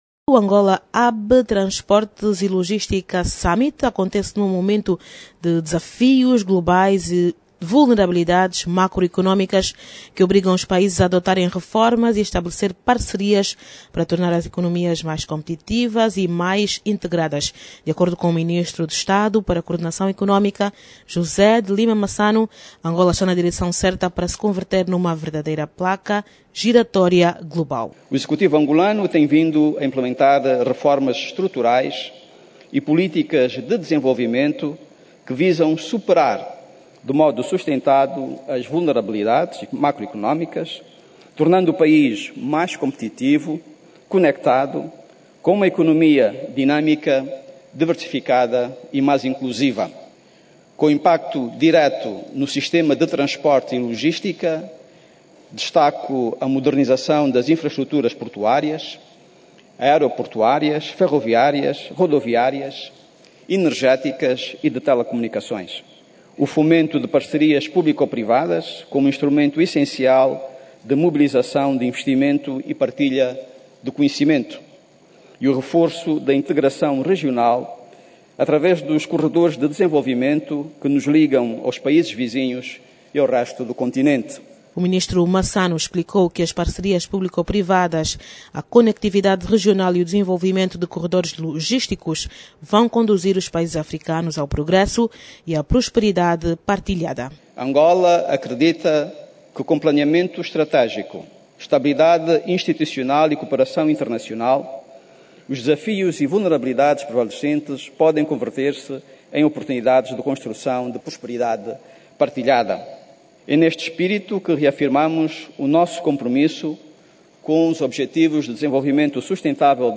O ministro falava esta manhã na sessão de abertura da Conferência Internacional sobre Transporte e Logística, que decorre a partir de hoje em Luanda, com duração de três dias.